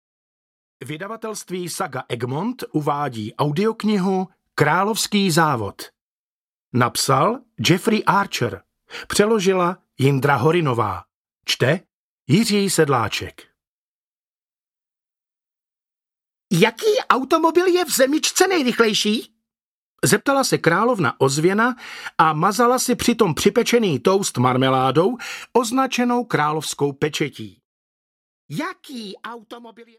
Královský závod audiokniha
Ukázka z knihy